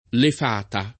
fato [ f # to ] s. m.